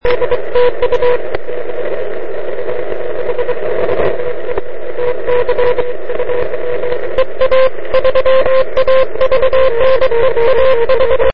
Pro ty méně zkušené - ukázky berte jako zvukové etudy výuky zašuměných signálů ( a signálů patlalů ).